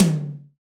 TOM TM074.wav